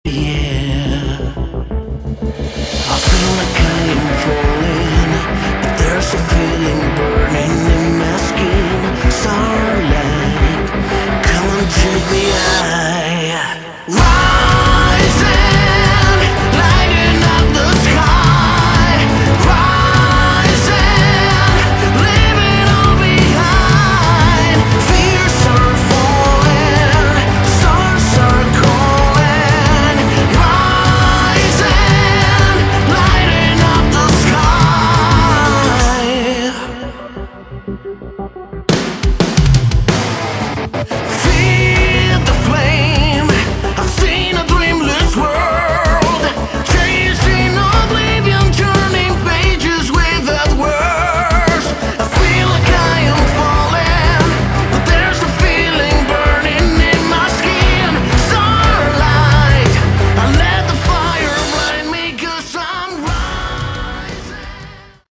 Category: Melodic Metal
vocals, guitars, bass, piano, programming
drums
backing vocals